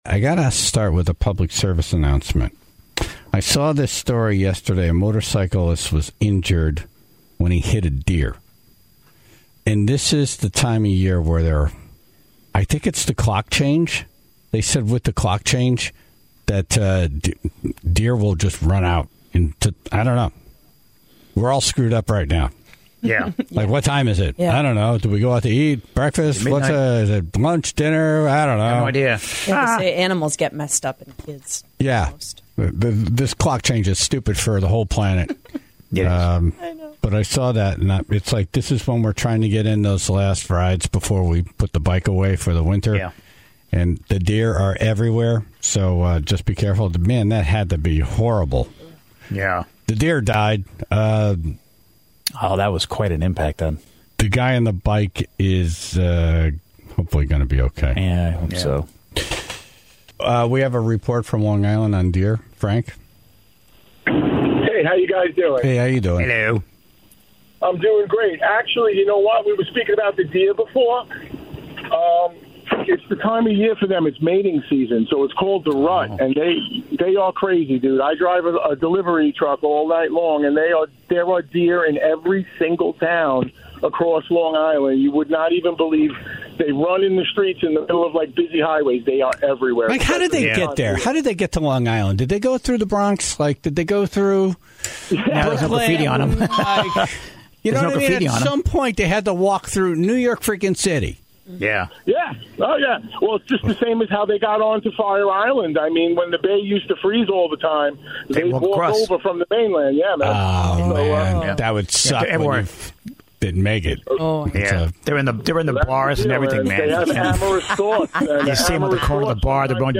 The Tribe called in to share some of their stories involving accidents with deer, and to share tips on how to properly hit them (if needed.)